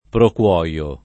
proquoio [ prok U0L o ]